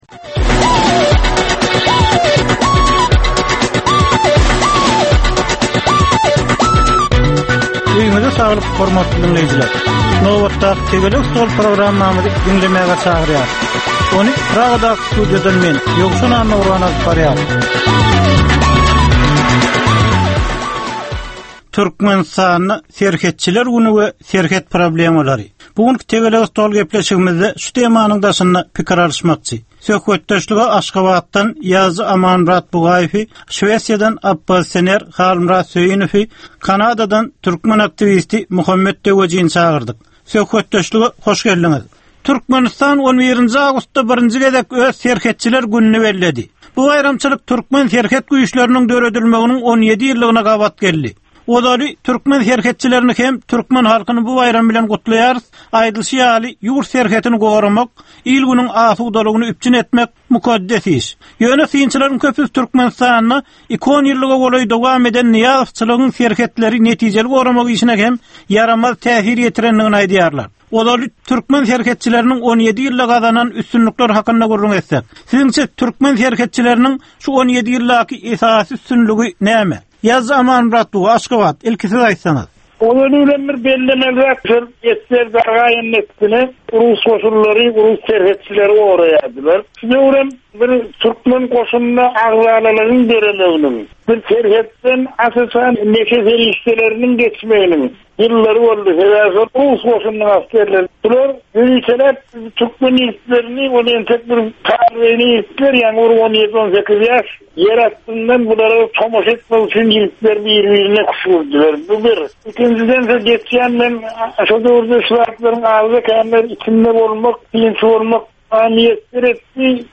Jemgyýetçilik durmusynda bolan ýa-da bolup duran sonky möhum wakalara ýa-da problemalara bagyslanylyp taýyarlanylýan ýörite Tegelek stol diskussiýasy. 30 minutlyk bu gepleshikde syýasatçylar, analitikler we synçylar anyk meseleler boýunça öz garaýyslaryny we tekliplerini orta atýarlar.